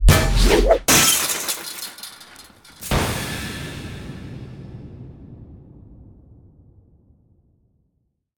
rooftopDoorSmash.ogg